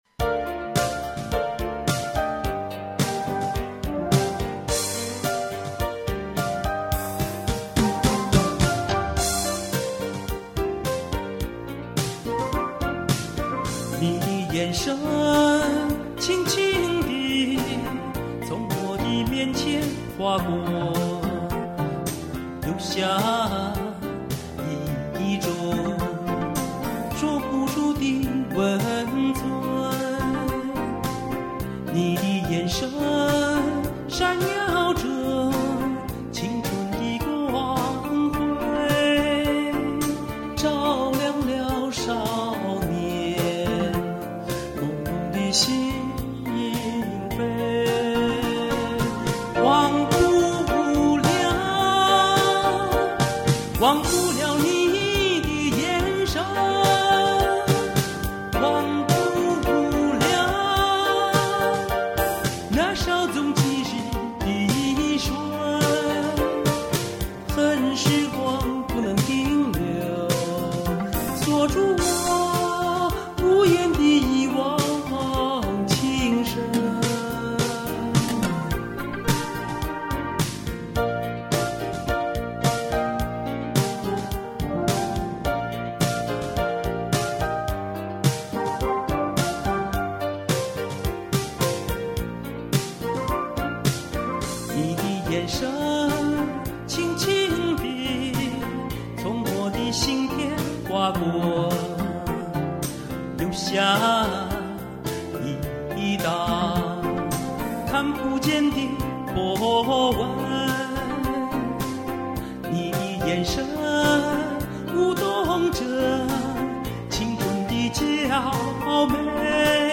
後記：因為時間實在太緊張，錄音是在第一次錄音的基礎上修改的,請大家諒解。